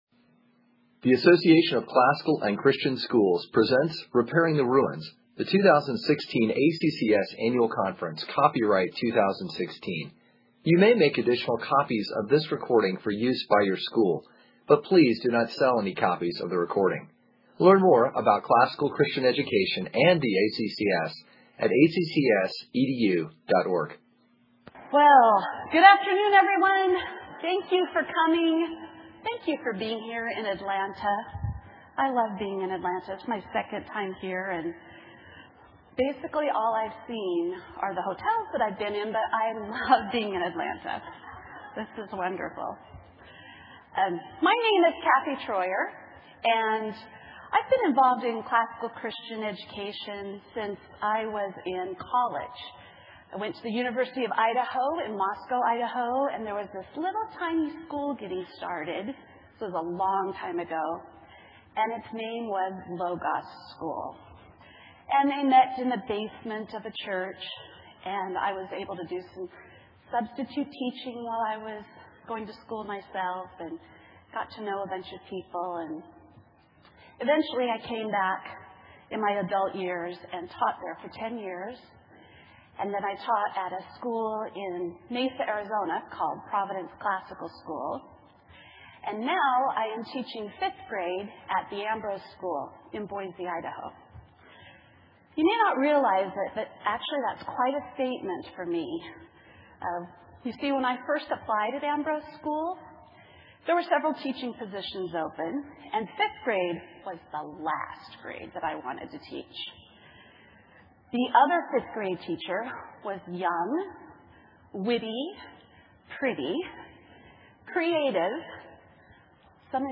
2016 Workshop Talk | 0:59:05 | All Grade Levels, Literature
Additional Materials The Association of Classical & Christian Schools presents Repairing the Ruins, the ACCS annual conference, copyright ACCS.